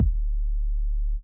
9kik.wav